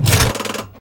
controlstick3.ogg